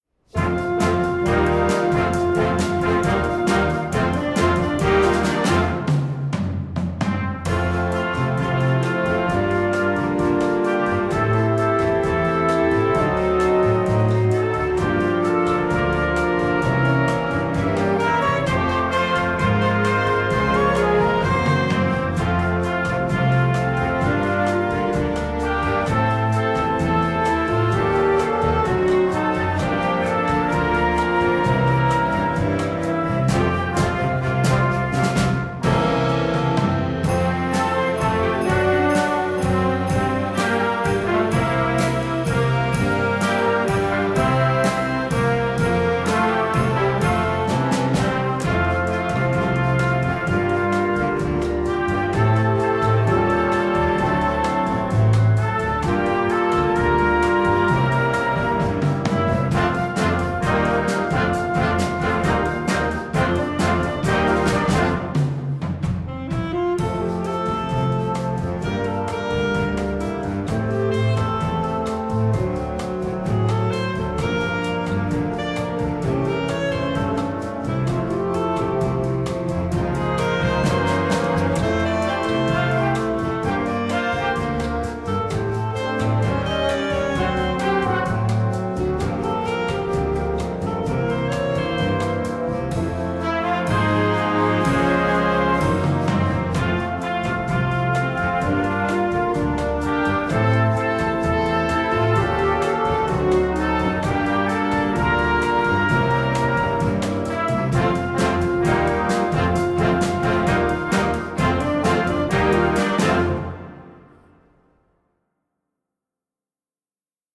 Gattung: Weihnachtslied für Jugendblasorchester
Besetzung: Blasorchester
Das im Bossa-Nova-Stil geschriebene Stück